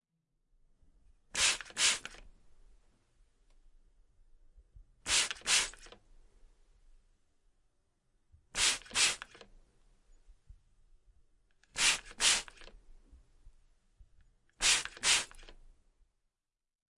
家居用品 " 活塞喷剂
描述：活塞式喷雾
Tag: 清洁 做家务 房子